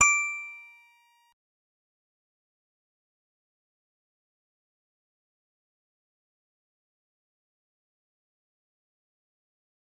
G_Musicbox-D7-mf.wav